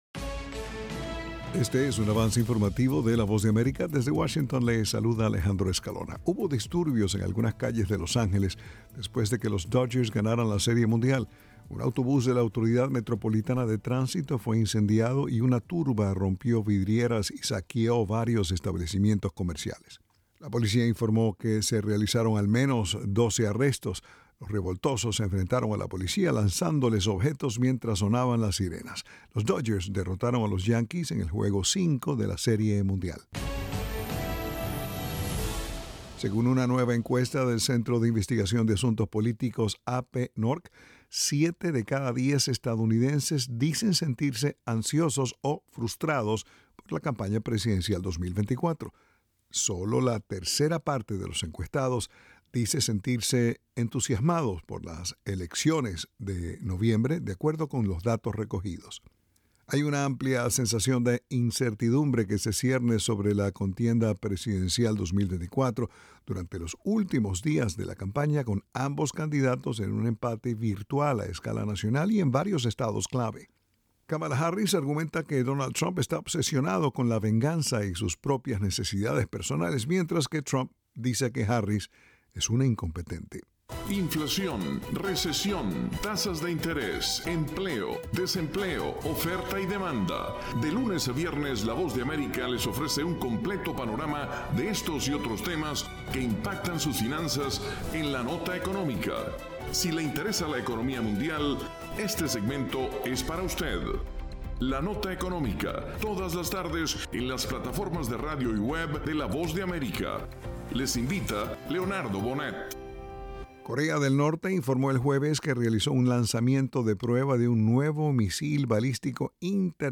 Avance Informativo
El siguiente es un avance informativo presentado por la Voz de América en Washington.